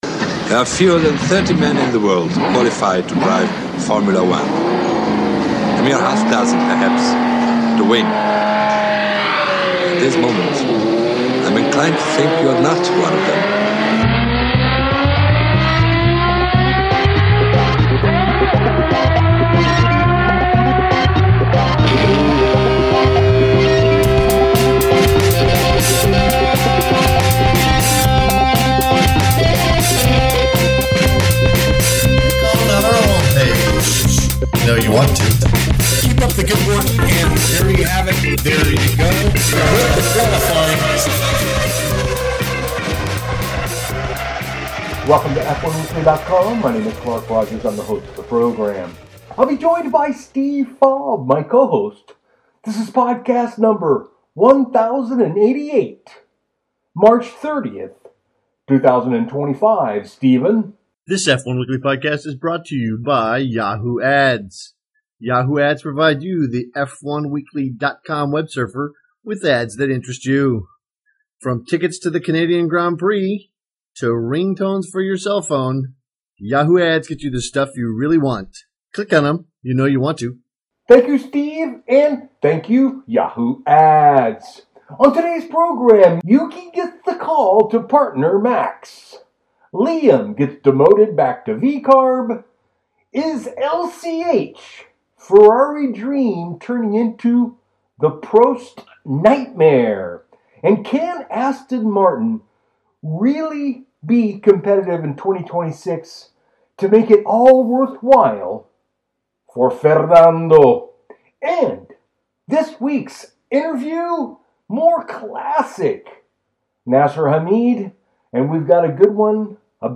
The Interview of a career, Formula 1 ® World Champion Nigel Mansell.